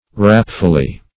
rapfully - definition of rapfully - synonyms, pronunciation, spelling from Free Dictionary Search Result for " rapfully" : The Collaborative International Dictionary of English v.0.48: Rapfully \Rap"ful*ly\ (r[a^]p"f[.u]l*l[y^]), adv.
rapfully.mp3